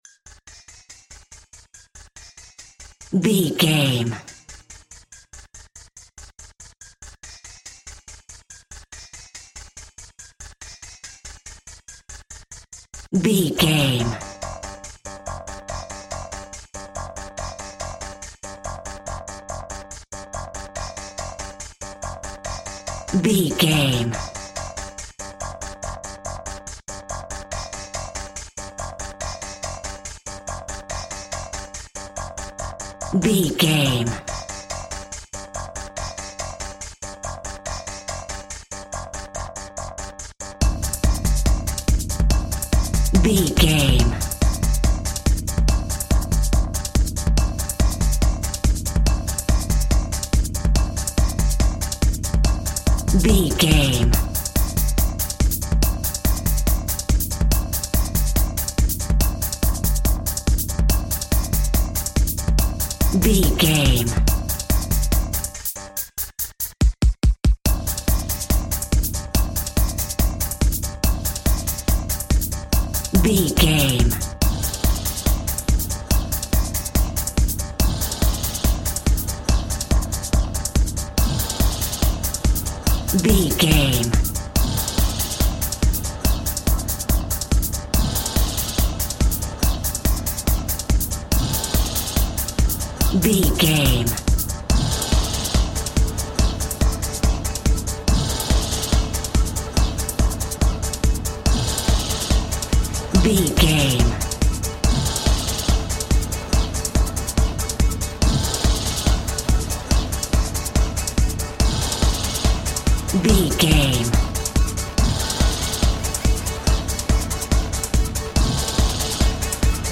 Electronic Experimental.
Epic / Action
Fast paced
Atonal
B♭
aggressive
dark
driving
intense
techno
industrial
glitch
synth lead
synth bass
electronic drums
Synth Pads